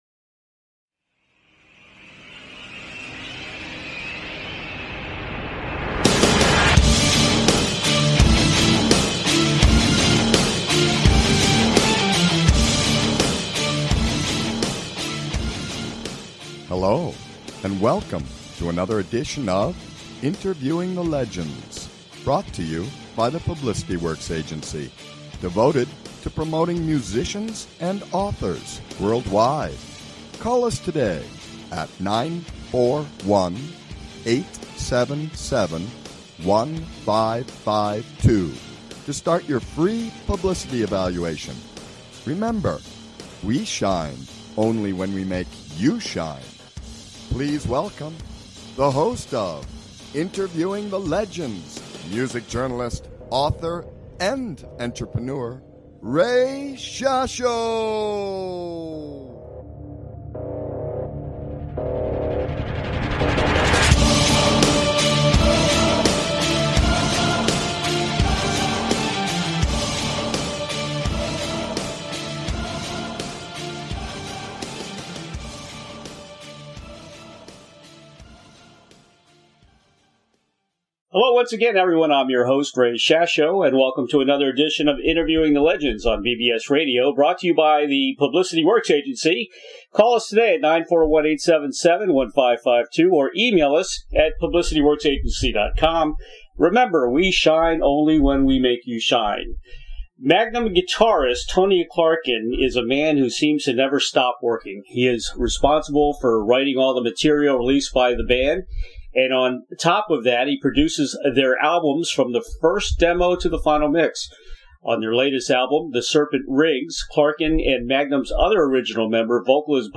Guest, Bob Catley